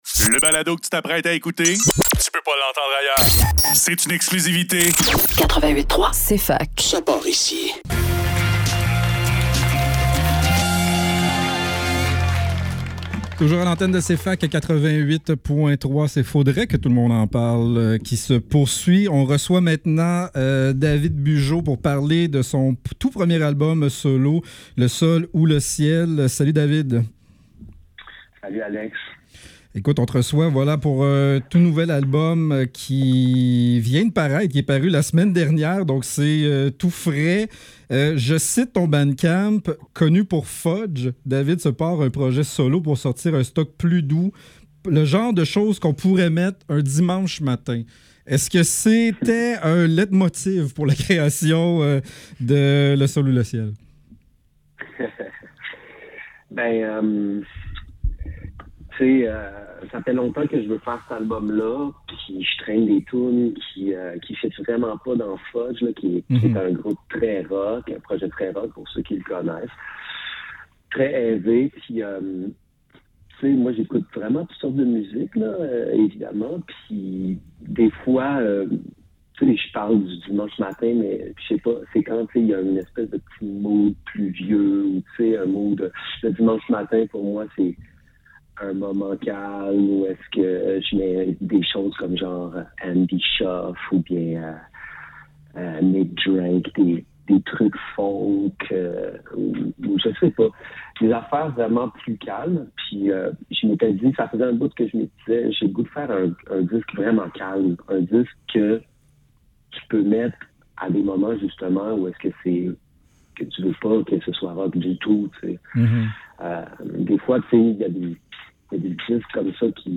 Faudrait que tout l'monde en parle - Entrevue